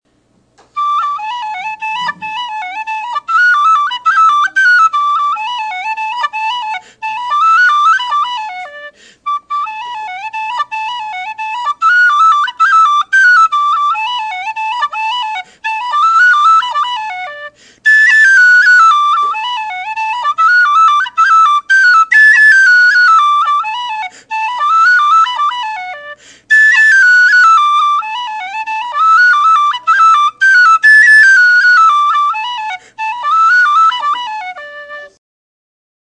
Sound clips of the whistle:
And it doesn’t have much start-of-note chiff. But it definitely has tone chiff throughout it’s sound.
Volume: Loud.